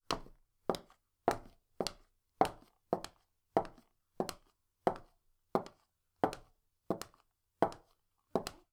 Womens_shoes_3.wav